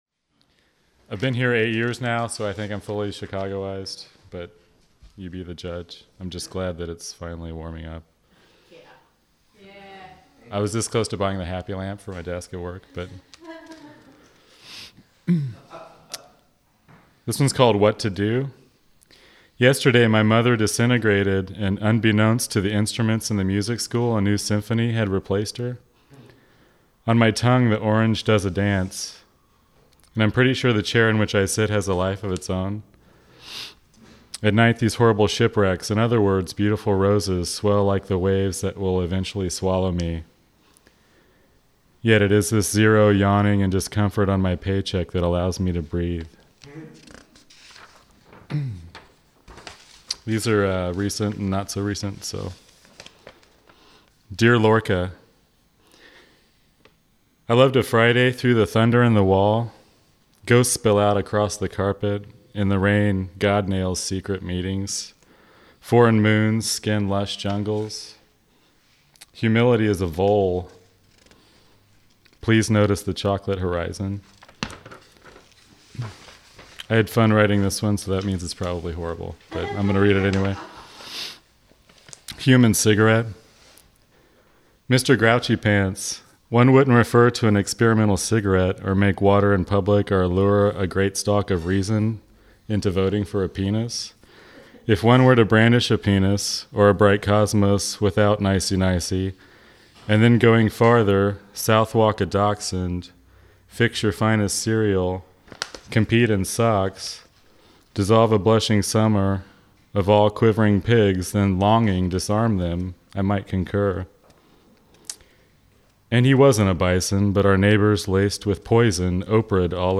11 Mbytes This set is a reading recorded live at the St. Paul's Cultural Center, operated by the Near Northwest Arts Council, Chicago, on 3 April 2009.